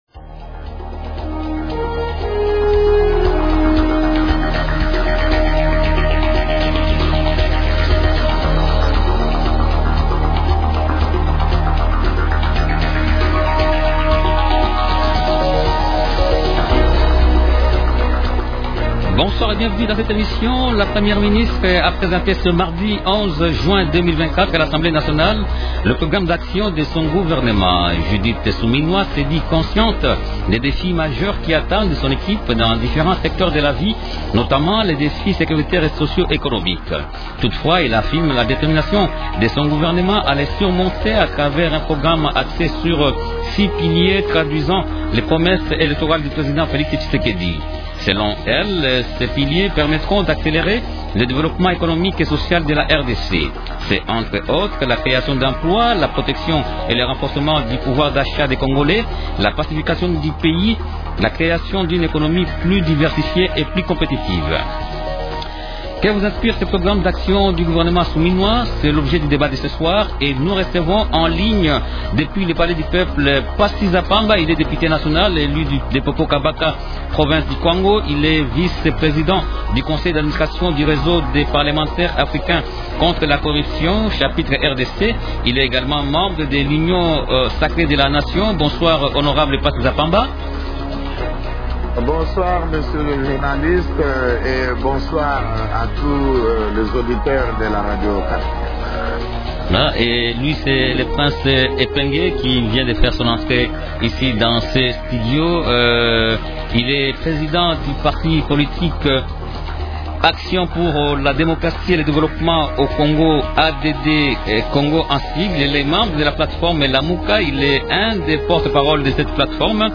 -Pasi Za Pamba, député national, élu de Popo Kabaka (Kwango) pour la 4è fois consécutive.